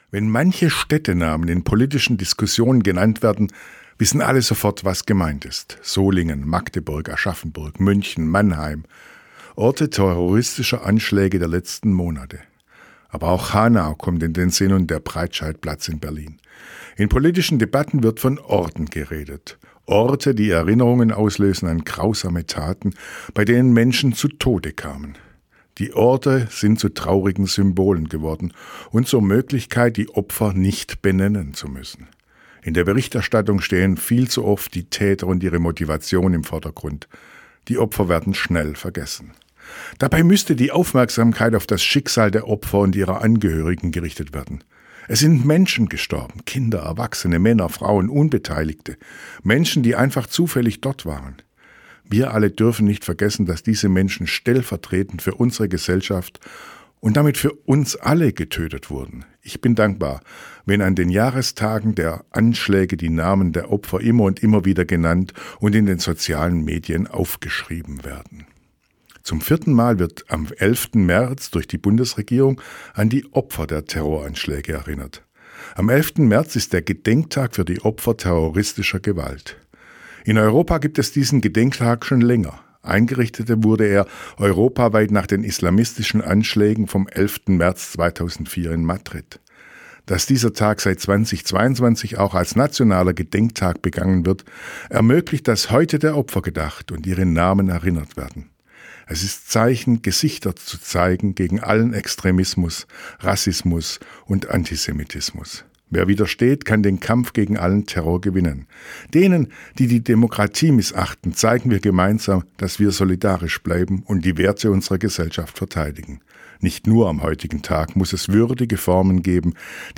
Radioandacht vom 11. März